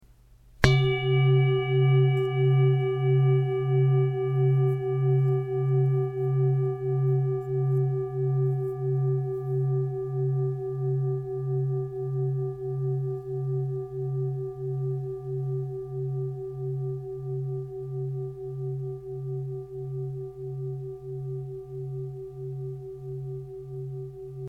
Tibetische Klangschale - ERDE JAHRESTON OM + PHOLUS
Durchmesser: 22,0 cm
Grundton: 134,60 Hz
1. Oberton: 382,36 Hz